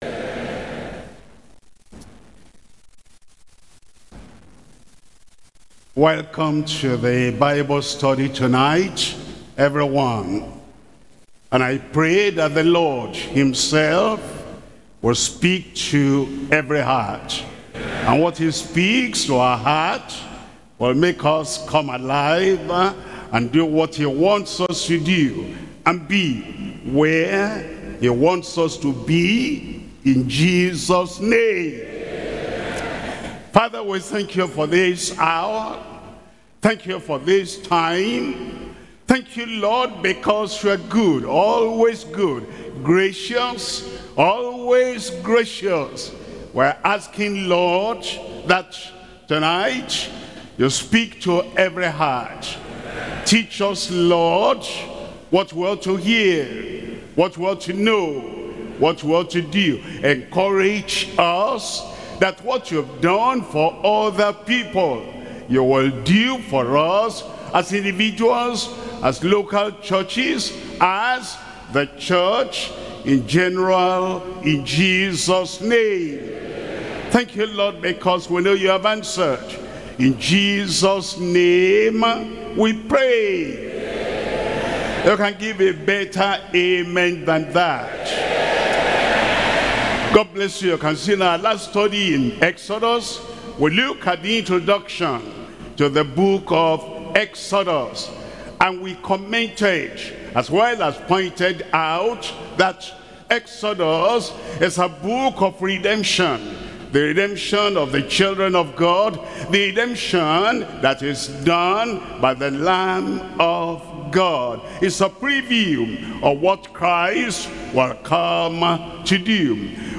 Sermons - Deeper Christian Life Ministry
Pastor W.F. Kumuyi